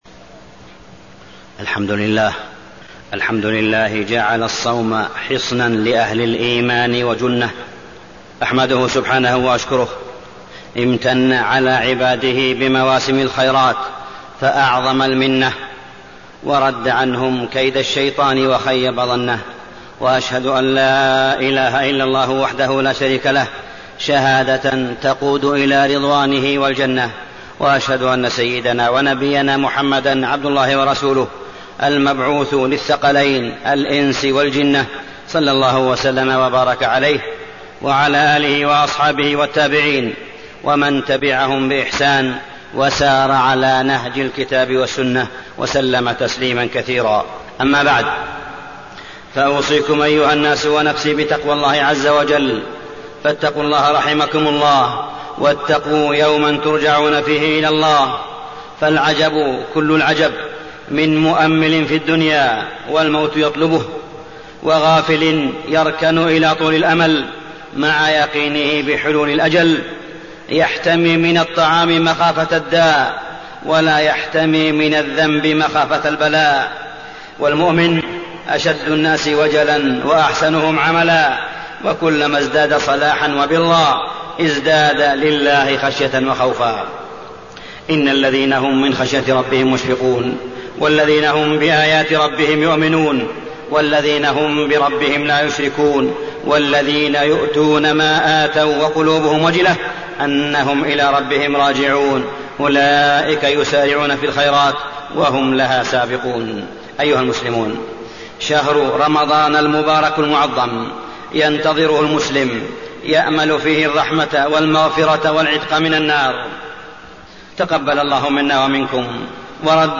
تاريخ النشر ٧ رمضان ١٤٣٠ هـ المكان: المسجد الحرام الشيخ: معالي الشيخ أ.د. صالح بن عبدالله بن حميد معالي الشيخ أ.د. صالح بن عبدالله بن حميد التحذير من إنتشار الفتن في الإعلام وغيره